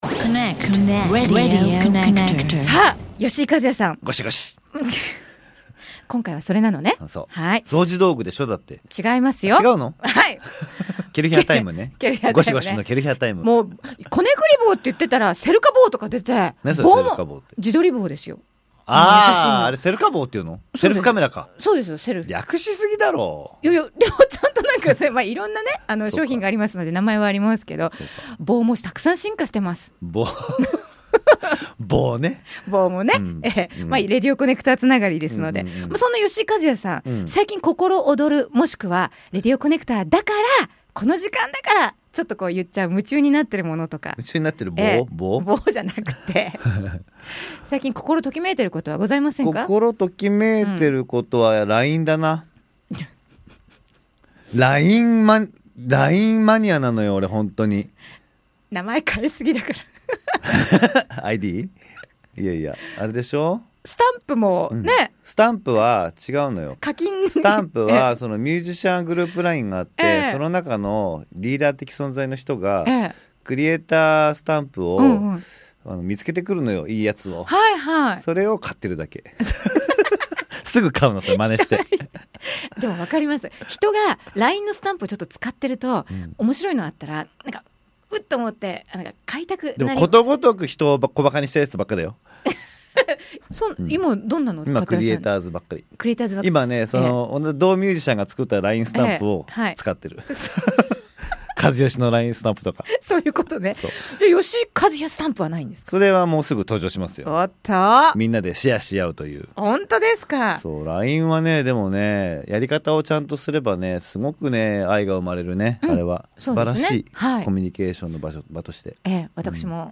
吉井和哉さん5週連続Specialインタビューの4夜目。